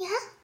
miya语音包重录